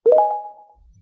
success.ogg